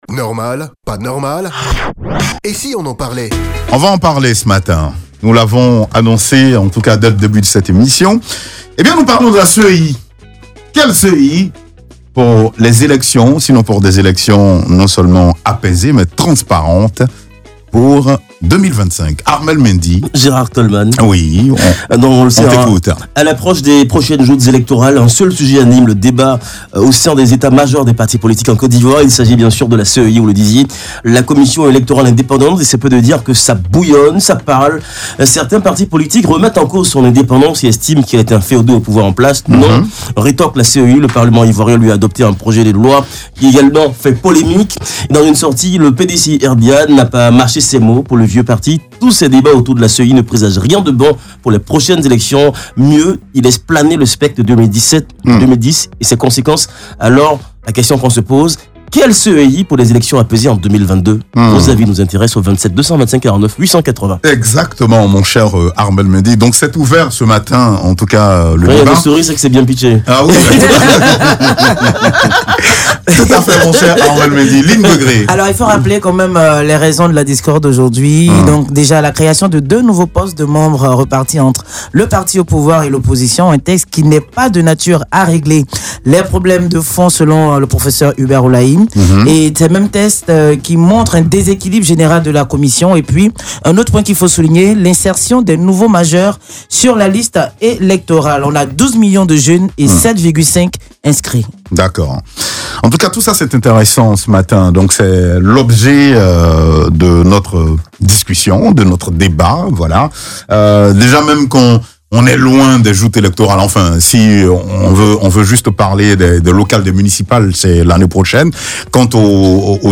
On en parle dans le Life Matin avec les auditeurs.